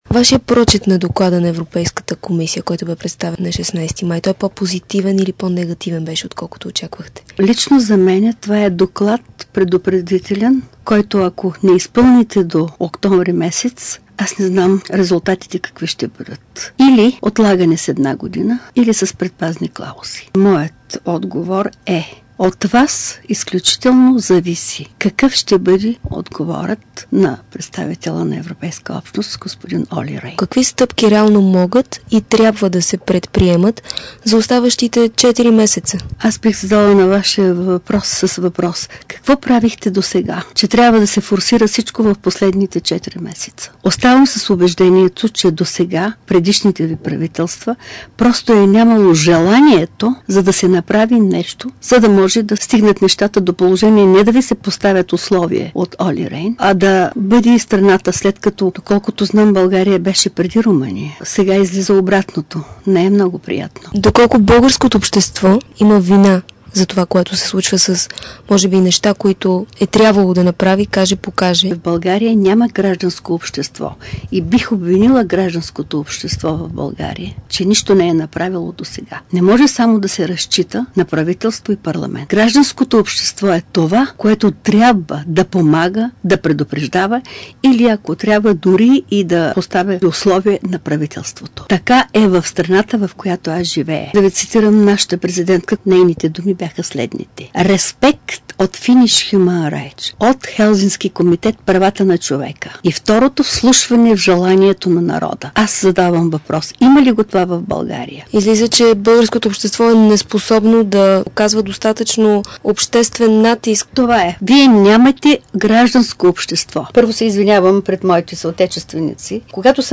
DarikNews audio: Интервю